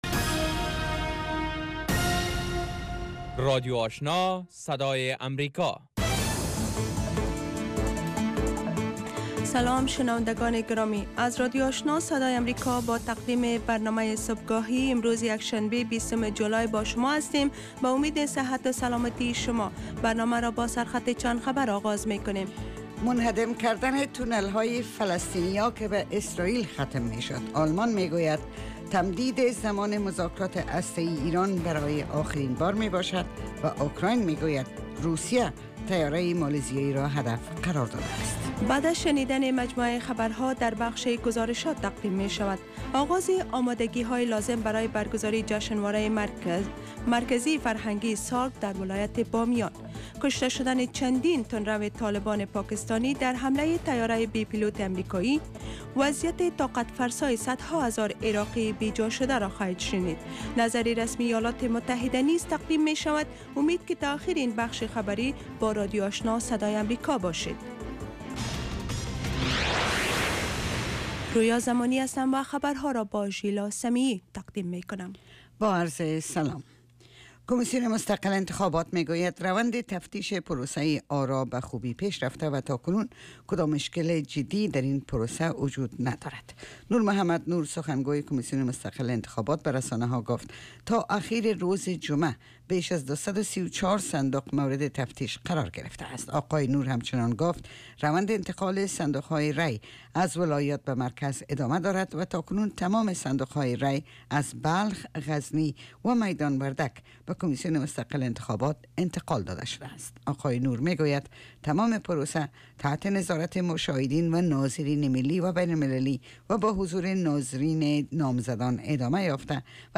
برنامه خبری صبح